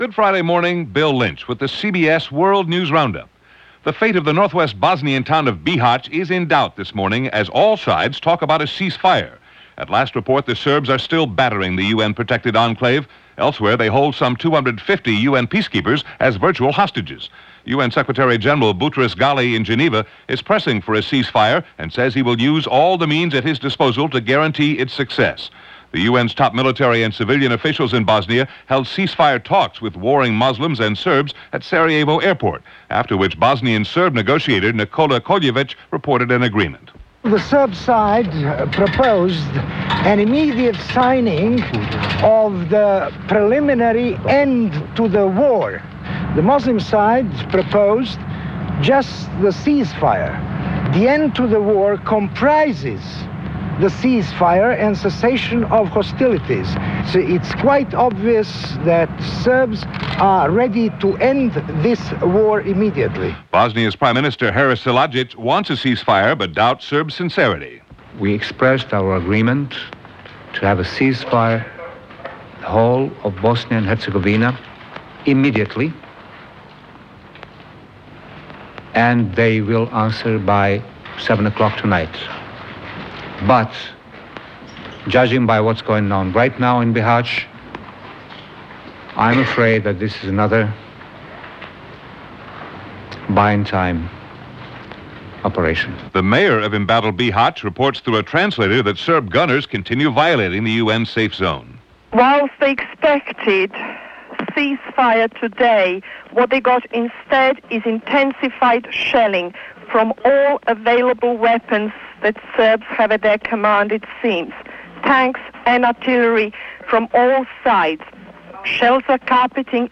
And that’s a small slice of what happened, November 25, 1994 as presented by The CBS World News Roundup.